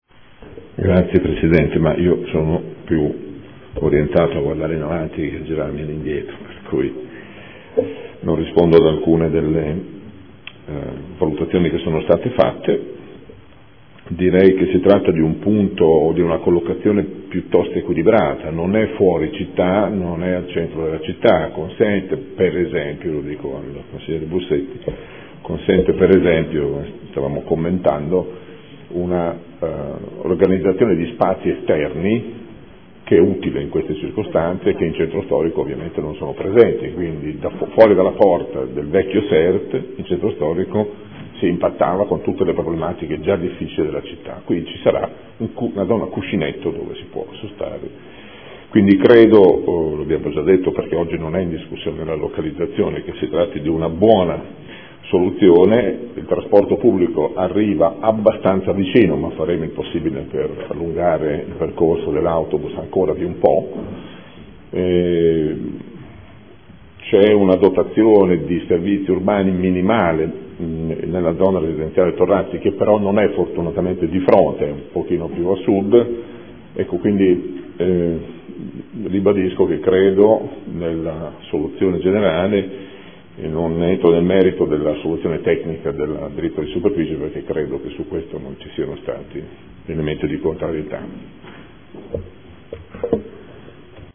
Seduta del 26 febbraio. Proposta di deliberazione: Concessione in diritto di superficie a titolo oneroso dell’immobile (terreno e fabbricato) posto a Modena in via Nonantolana n. 1010 a favore dell’Azienda USL di Modena – Approvazione.